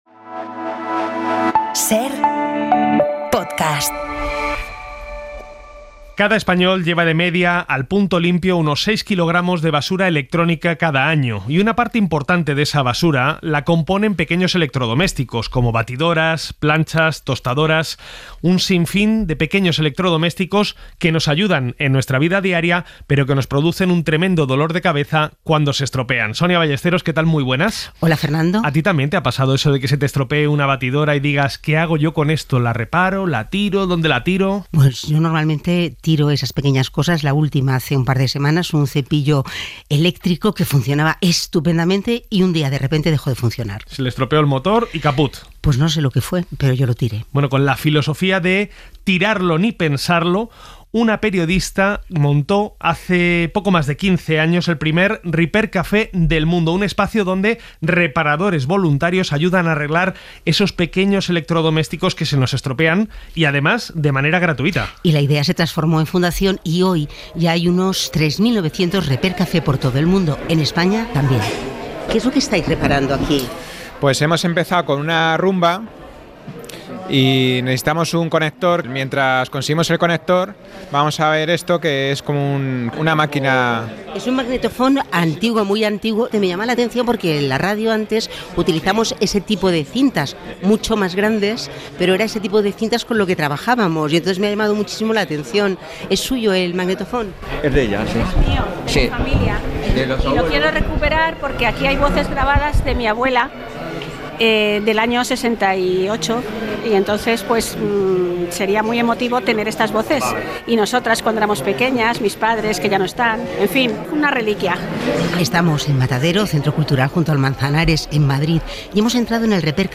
Cada vez más personas optan por reparar los pequeños electrodomésticos que se averían en lugar de tirarlos al punto limpio. Visitamos un taller donde nos enseñan a hacerlo y hablamos con algunas de las personas que prefieren dar una segunda vida a sus productos por cuestiones de ahorro económico y ecológico.